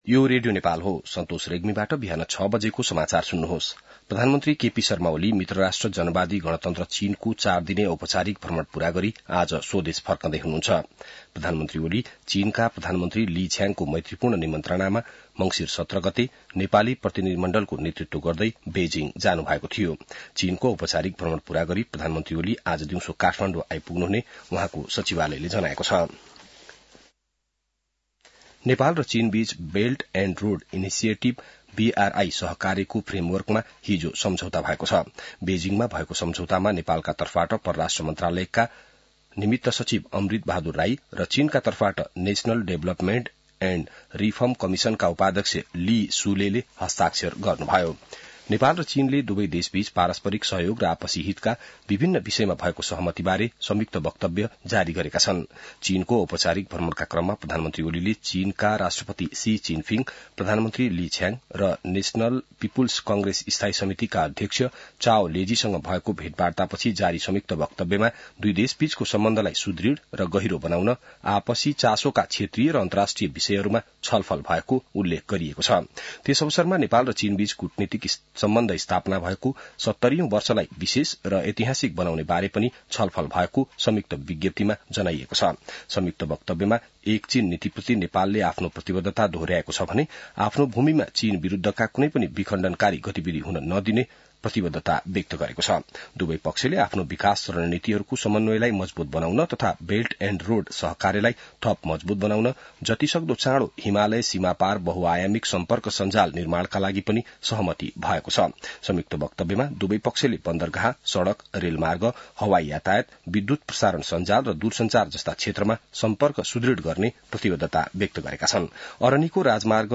बिहान ६ बजेको नेपाली समाचार : २१ मंसिर , २०८१